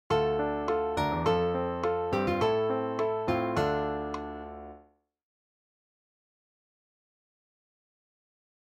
Dm  F  Am  Dm [